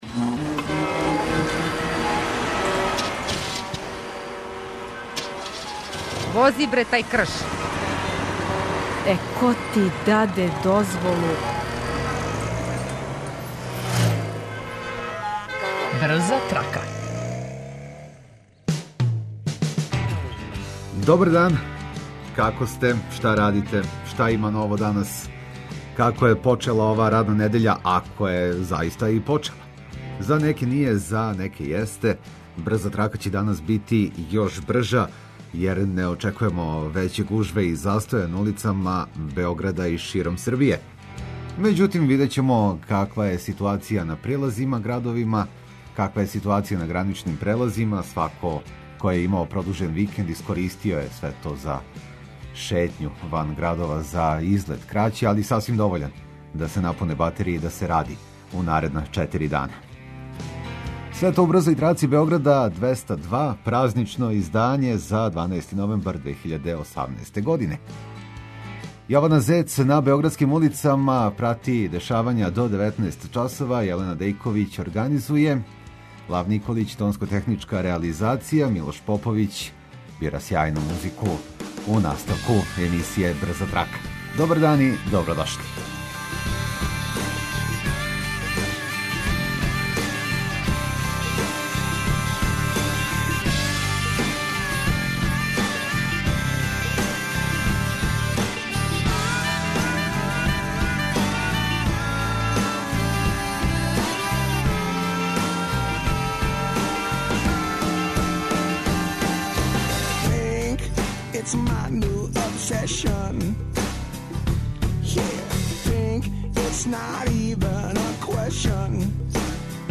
Слушаоци репортери јављају новости из свог краја, па нам се јавите и ви.